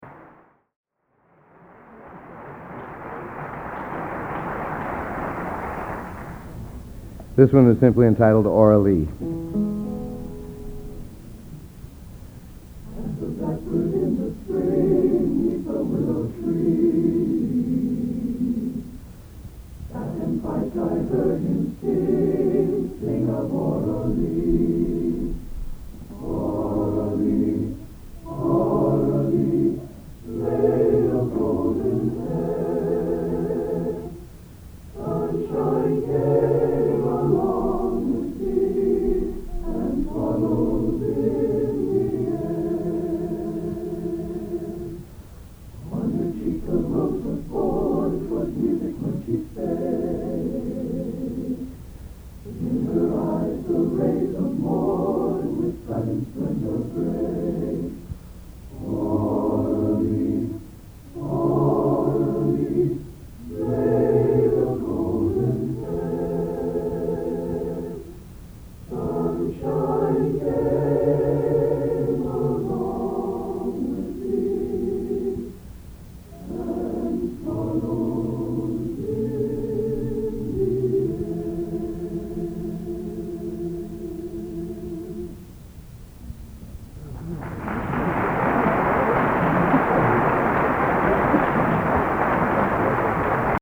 Location: West Lafayette, Indiana
Genre: Traditional | Type: End of Season